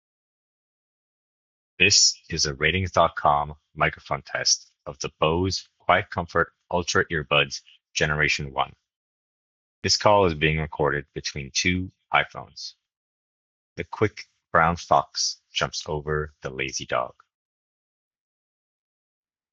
mic-call-recording.wav